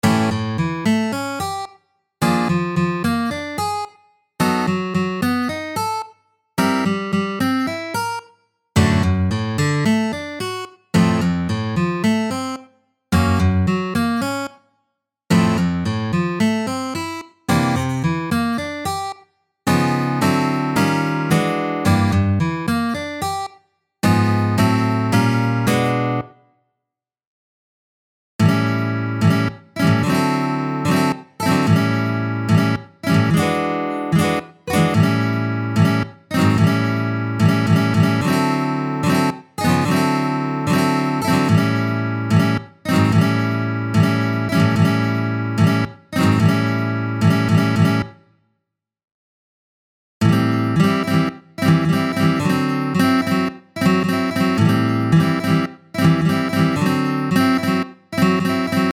• petit barré LA7, LA#7, SI7…
• séquence du I IV9 V9 blues
• séquence IIm7 V9